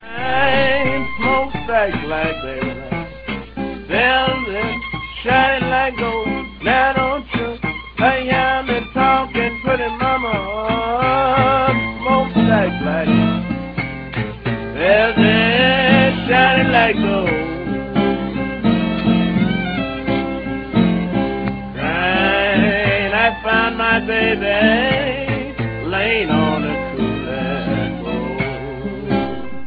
струнную группу
скрипач
вокалист, гитарист
но значительную часть его составляли блюзы